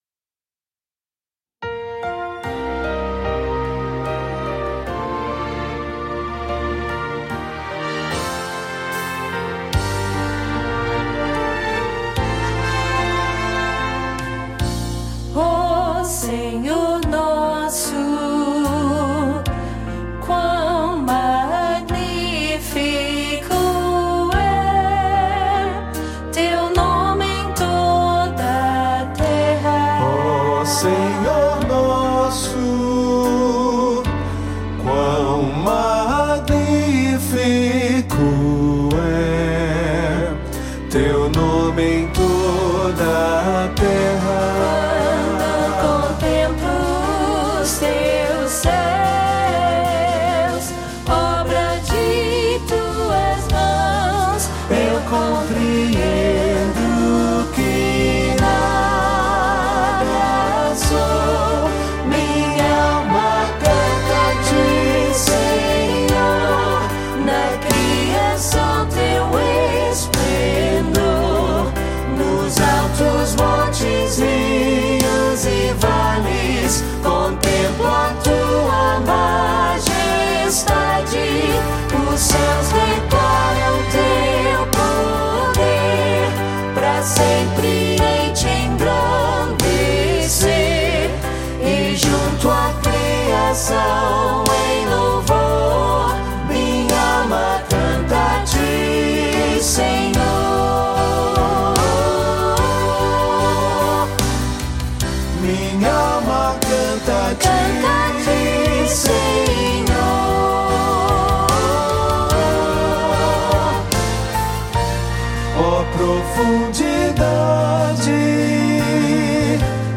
• Baixo
• Piano
• Bateria
• Teclados
• Órgão
• Percussão (Shaker)
🎼 Orquestração
• Violino I
• Viola
• Cello
• Trompete I e II
• Trompa I e II
• Trombone I, II e III
• Flauta I e II